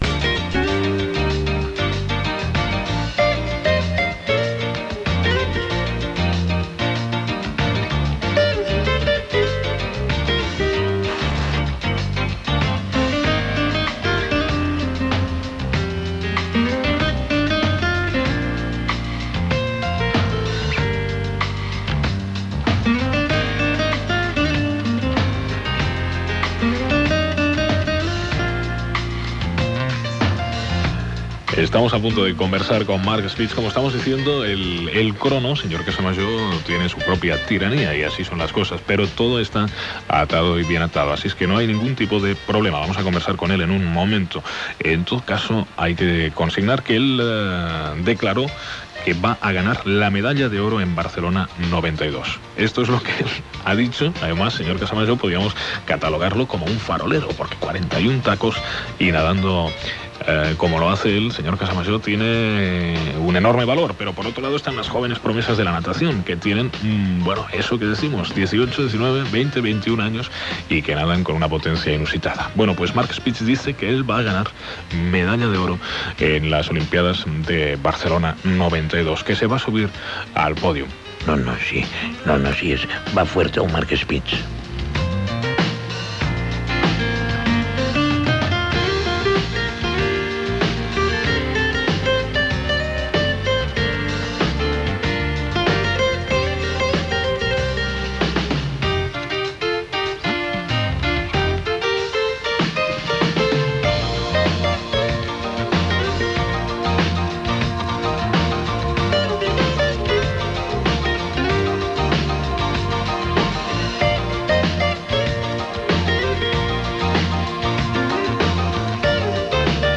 Minuts previs i entrevista al nedador Mark Spitz sobre la seva trajectòria esportiva i els Jocs Olímpics de Barcelona 1992 Gènere radiofònic Info-entreteniment